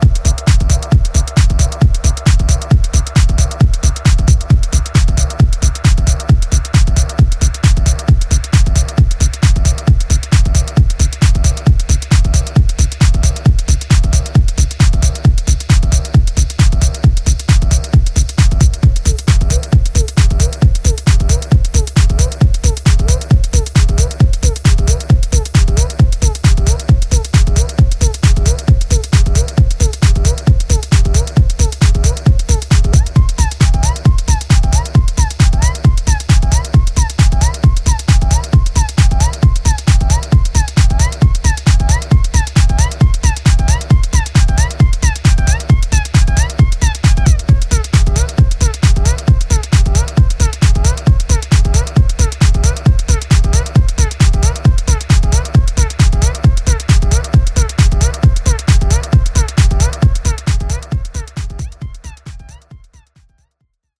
gets a stripped back, DJ friendly remix